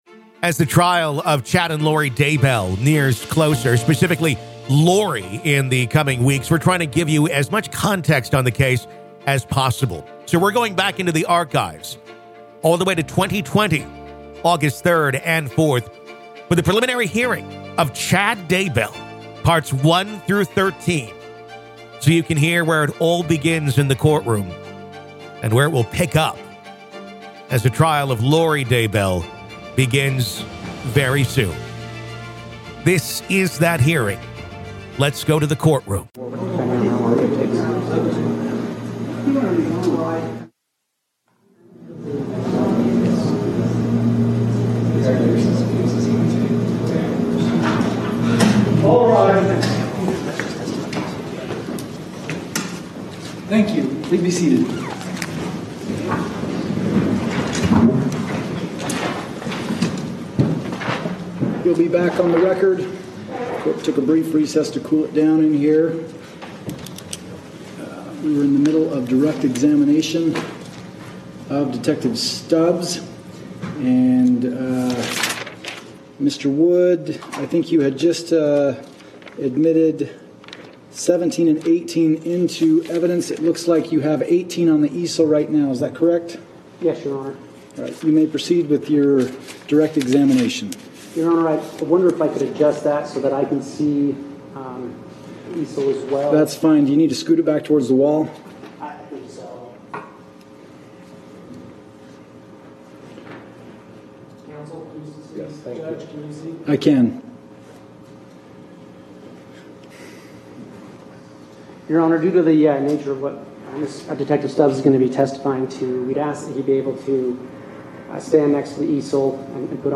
Listen To The Full Preliminary Hearing Of Chad Daybell, Part 7
This is the complete preliminary hearing of Chad Daybell, originally recorded August 3rd and 4th of 2020.